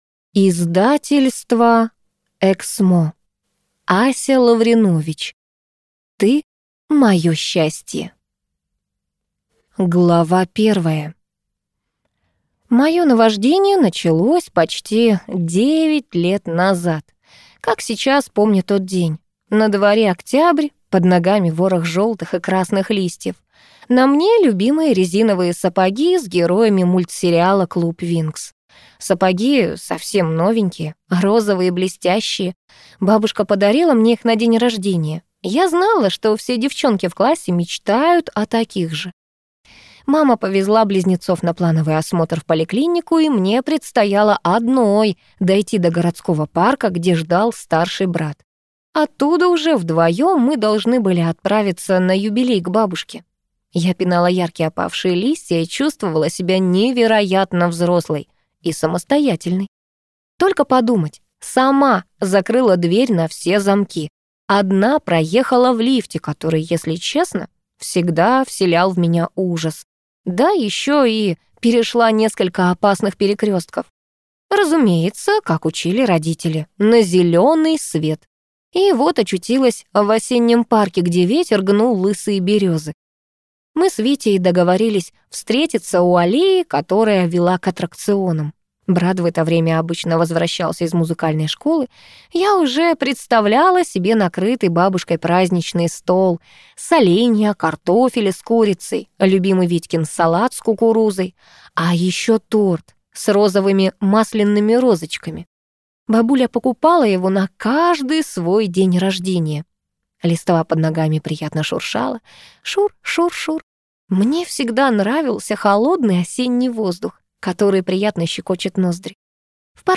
Аудиокнига Ты мое счастье | Библиотека аудиокниг